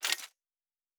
pgs/Assets/Audio/Sci-Fi Sounds/Weapons/Weapon 08 Foley 3 (Laser).wav at master
Weapon 08 Foley 3 (Laser).wav